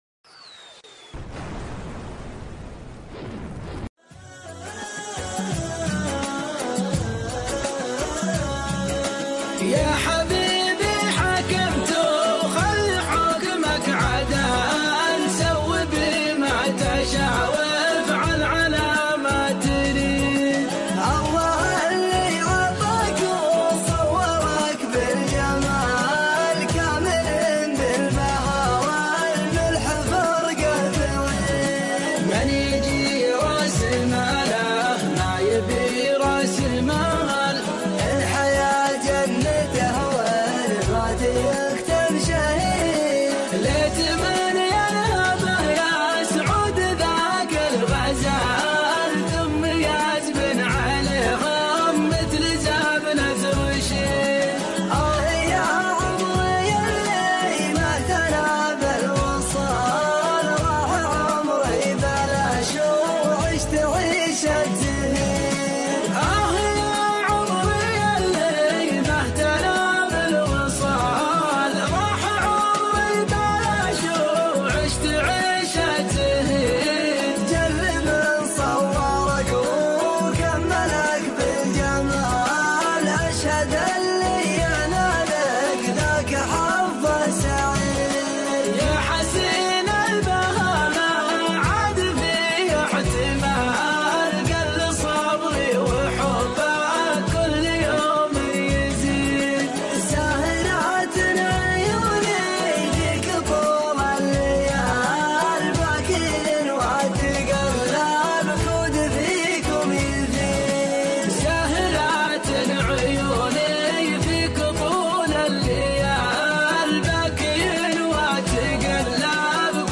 شيلة
طرب